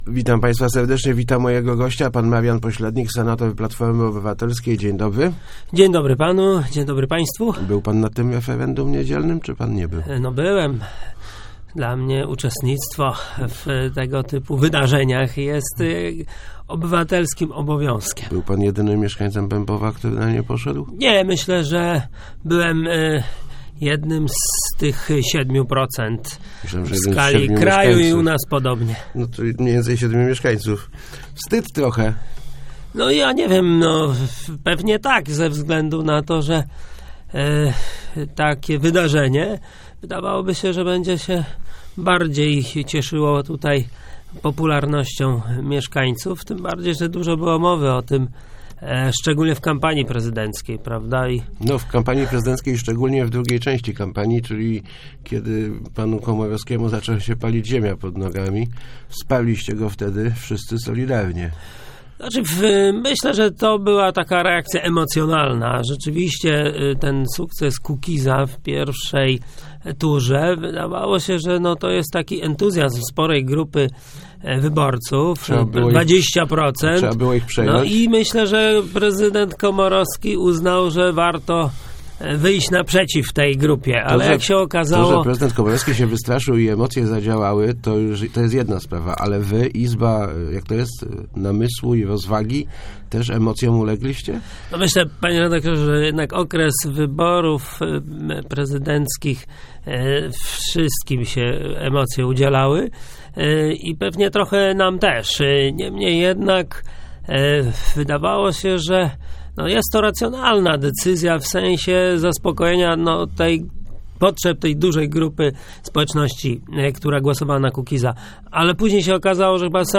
Nie oderwa�em si� od spo�eczno�ci lokalnej - zapewnia� w Rozmowach Elki senator PO Marian Po�lednik. Przekonywa�, �e ma nowe pomys�y na aktywno�� w kolejnych czterech latach, na przyk�ad poprzez wspó�prac� z PWSZ.